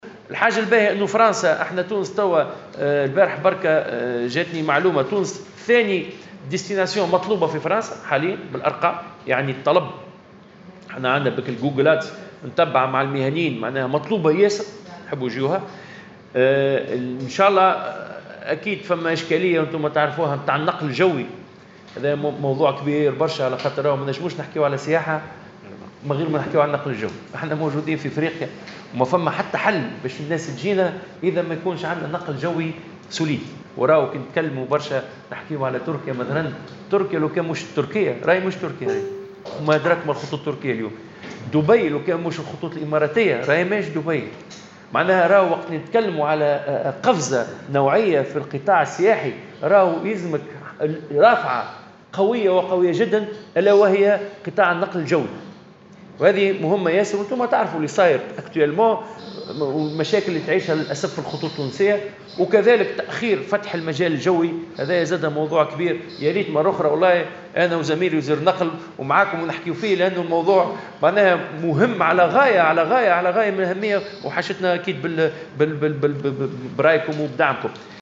وأشار خلال جلسة استماع اليوم في لجنة الفلاحة والأمن الغذائي والخدمات ذات الصلة، إلى أن المشاكل التي تعيشها الناقلة الوطنية وتأخير فتح المجال الجوي، كانا لهما انعكاسات سلبية على قطاع السياحة، مبيّنا أن أغلب البلدان التي عرفت قفزة سياحية نوعية على غرار تركيا ودبي تمتلك أسطول نقل جوي متطوّر.